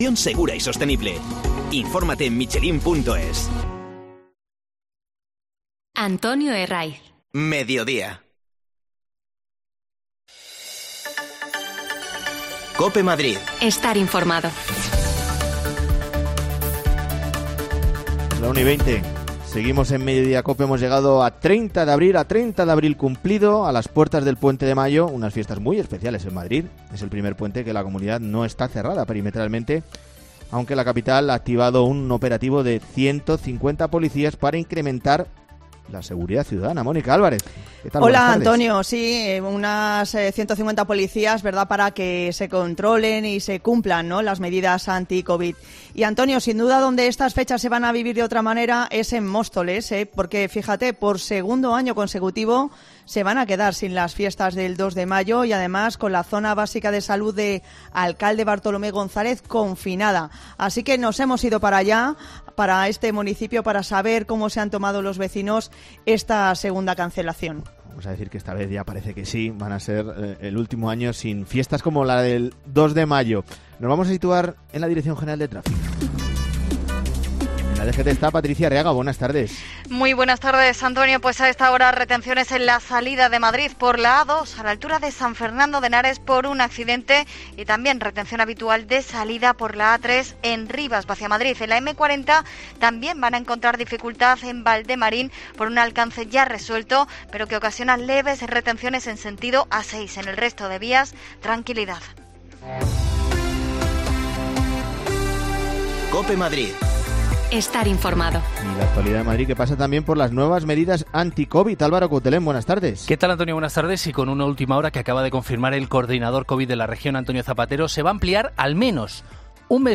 Nos acercamos hasta allí para hablar con sus vecinos
Las desconexiones locales de Madrid son espacios de 10 minutos de duración que se emiten en COPE , de lunes a viernes.